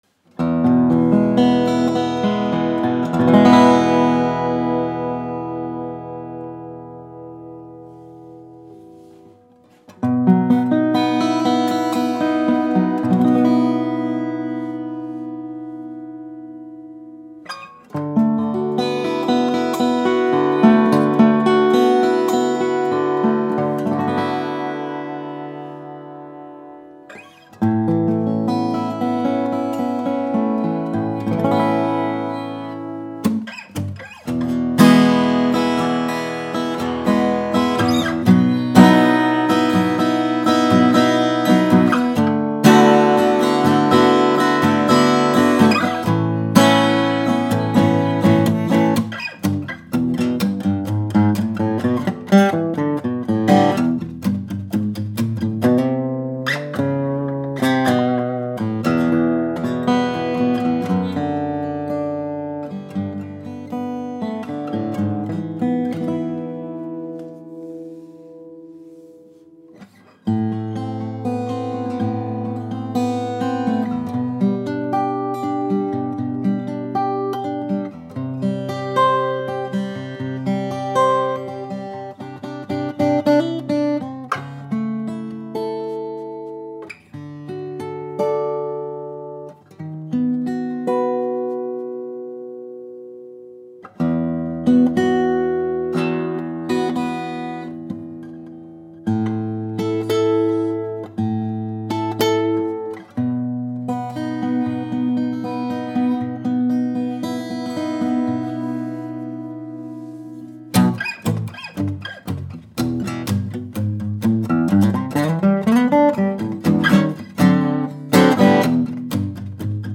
A Lowden S35 is the ultimate small-bodied guitar, bringing out the mid-range punch as well as a great response and feel.  Despite the compact body size, there is no sacrifice in tone, clarity or projection.
The AAAA Italian Alpine Spruce comes from very slow growing old trees and produces a very warm and clear sound.
This hard to get tonewood has a glassy bell like tone in the trebles and deep bass response. Compared to Madagascar, it has more of a sweetness to the trebles.